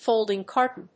folding+carton.mp3